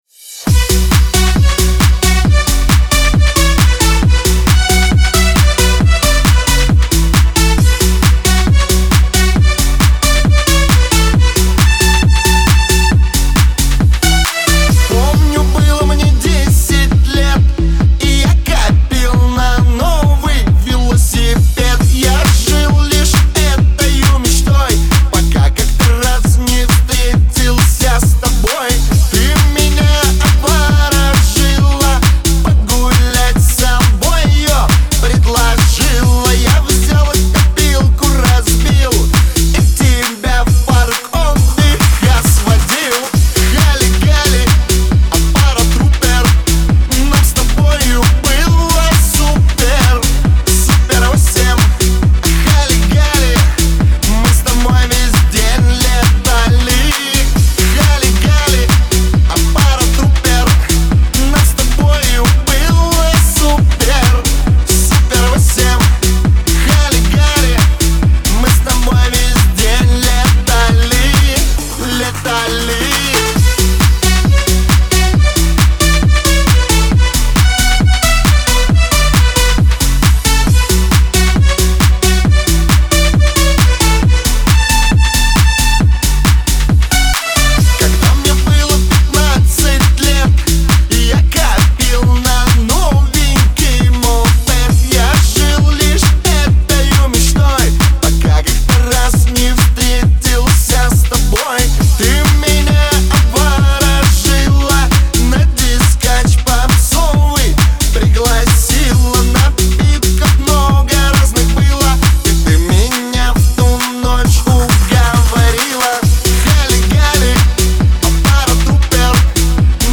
Кавер-версия
Веселая музыка
эстрада